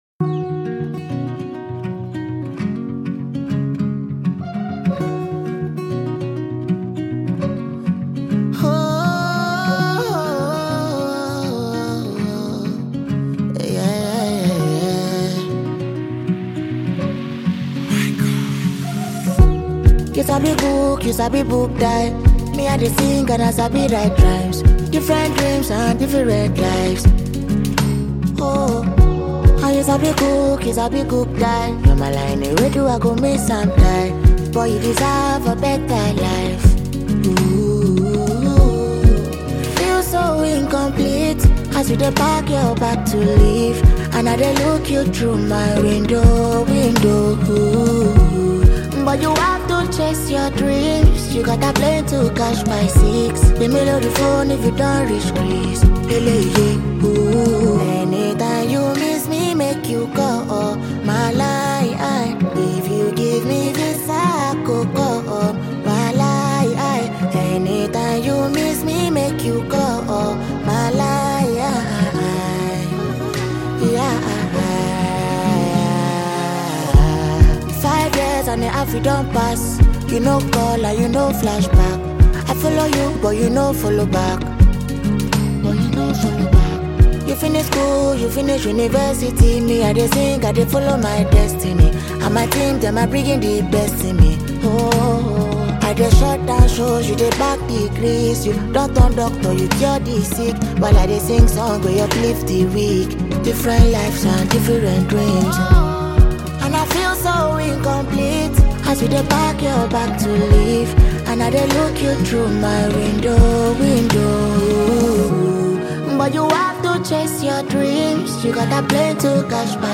Talented emerging Nigerian singer